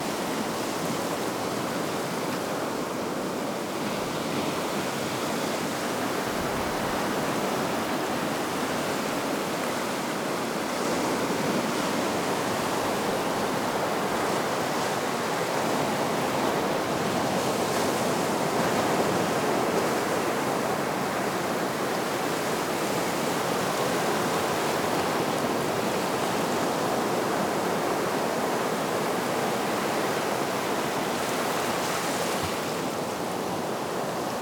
After a long day in the office, we went down to the water line for the first time this year.